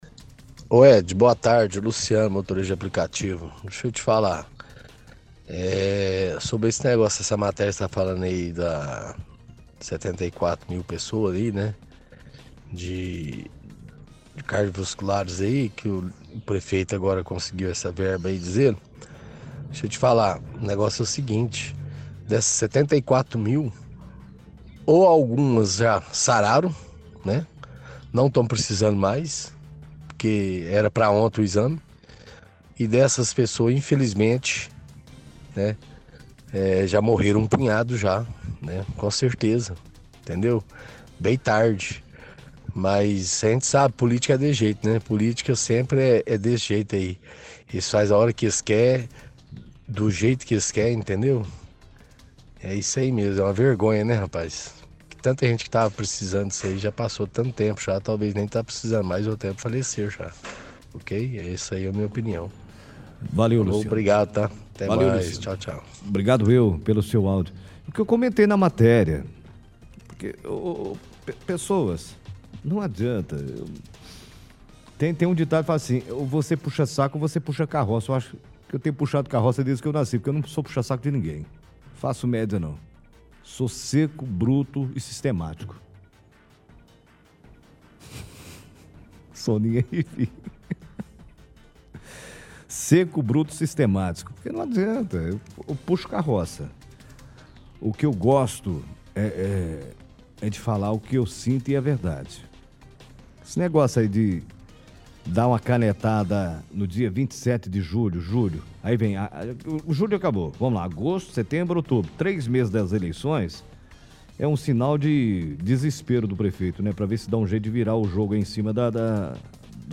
faz piada imitando como se estivesse fazendo narração de propaganda da prefeitura.